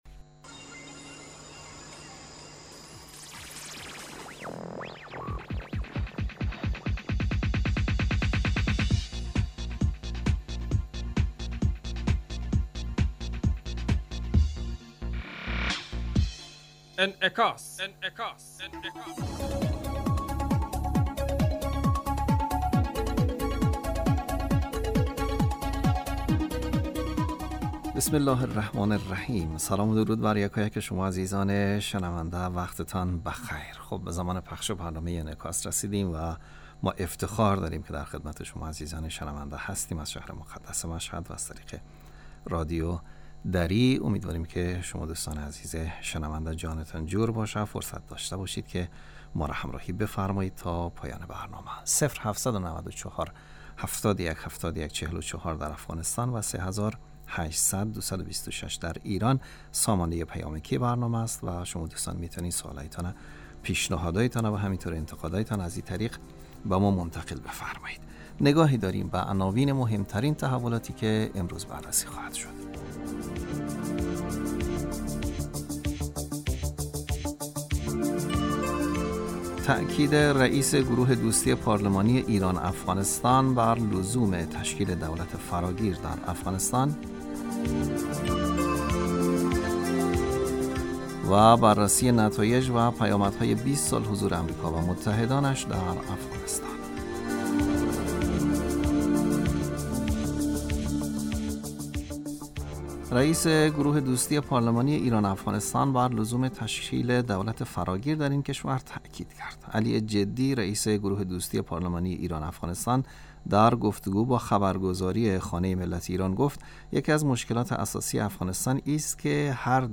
برنامه انعکاس به مدت 30 دقیقه هر روز در ساعت 12:00 ظهر (به وقت افغانستان) بصورت زنده پخش می شود.